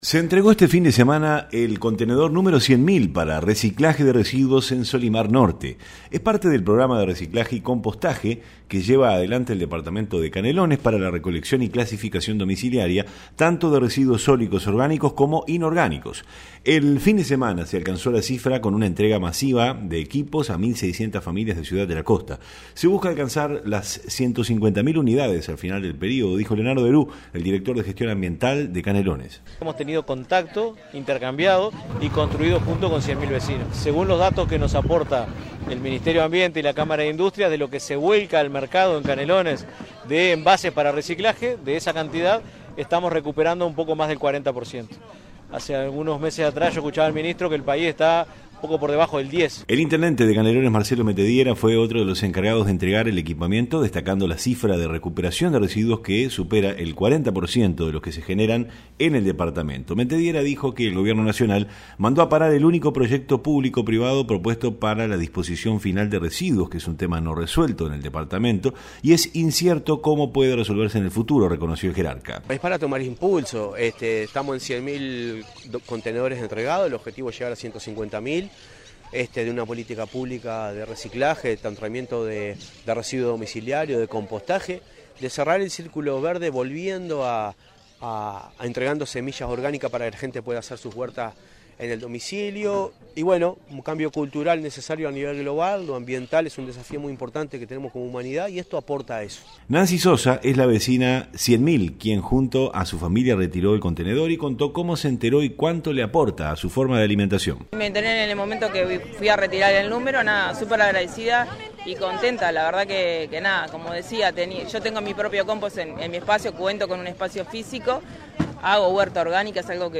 REPORTE-RECICLAJE.mp3